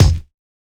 Dilla Kick 61.wav